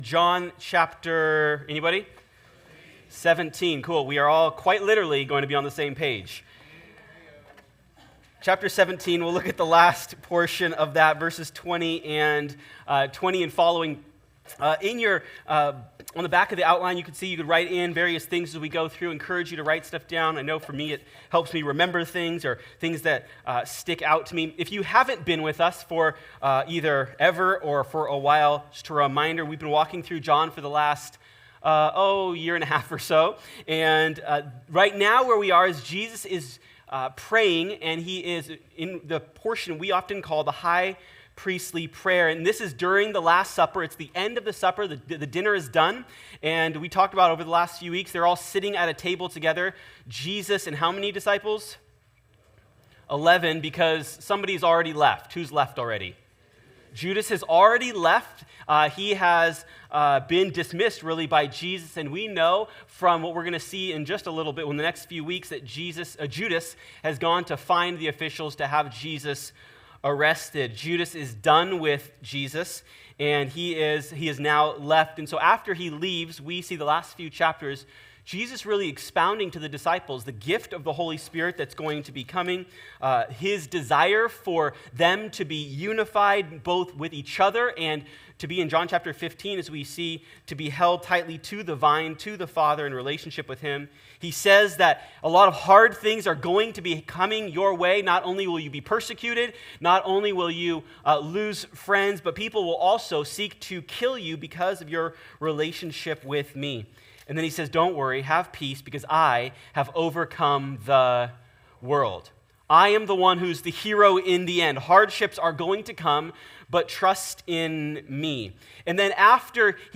Living Hope Church, Woodland